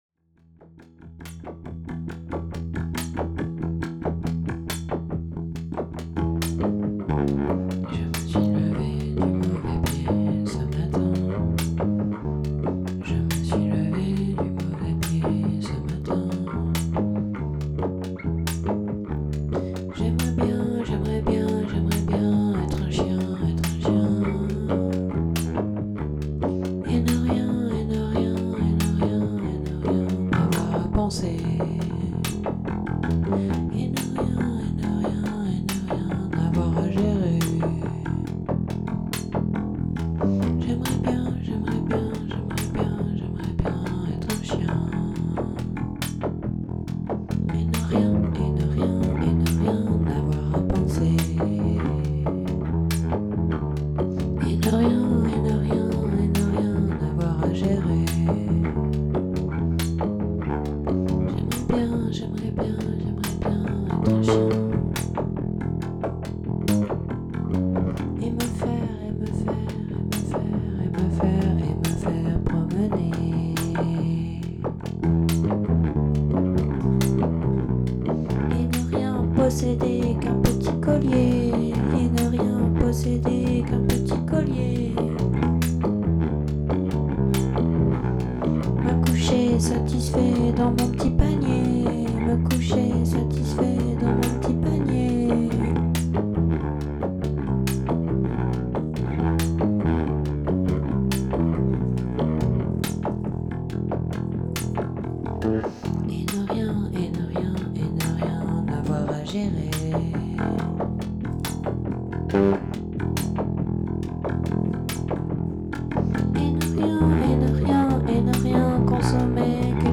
jamm session
enregistré dans le perche